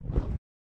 PixelPerfectionCE/assets/minecraft/sounds/mob/polarbear/step2.ogg at f70e430651e6047ee744ca67b8d410f1357b5dba